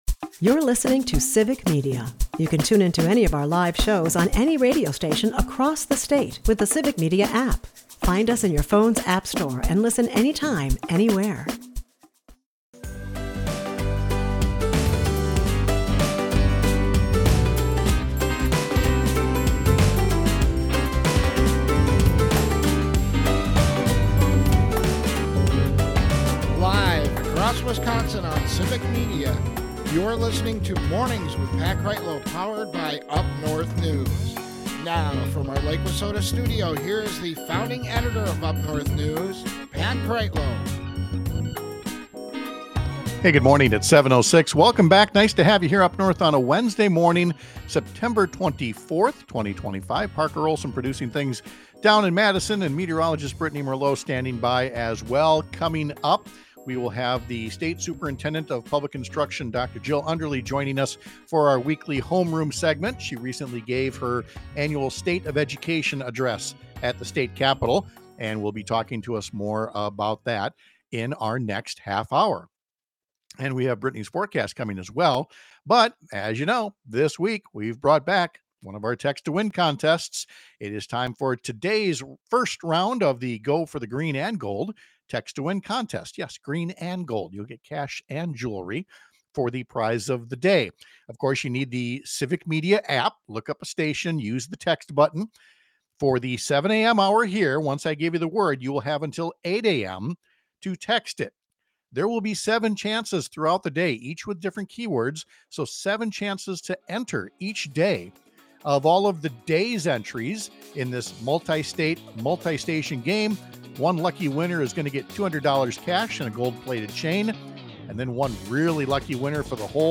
State superintendent of public instruction Jill Underly joins us to discuss her annual state of education address.
Guests: Dr. Jill Underly